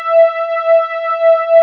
ANALOGE5.wav